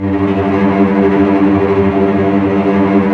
Index of /90_sSampleCDs/Roland - String Master Series/STR_Vcs Tremolo/STR_Vcs Trem f